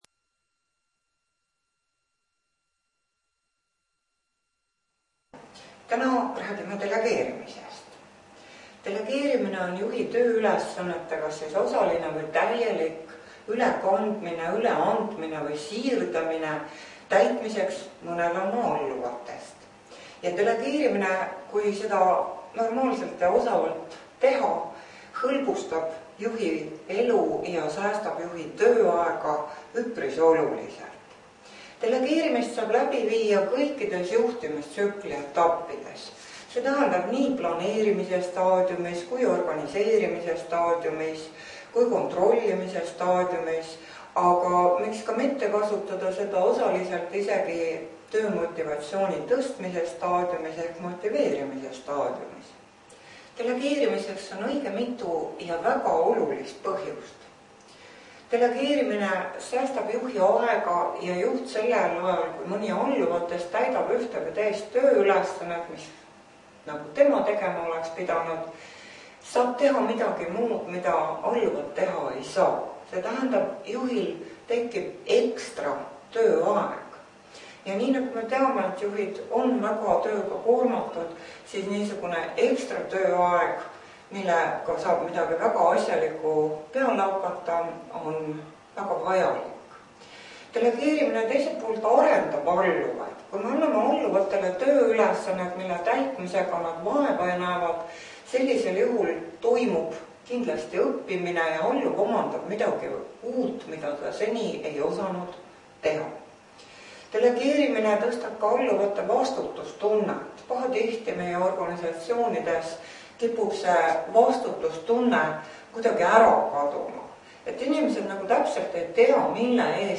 Delegeerimise loengu MAPP printimiseks Mapi lugemiseks vajate programmi Adobe Acrobat Reader kui teil seda arvutis ei ole siis leiate selle siit Delegeerimise loeng MP3 failina (18MB)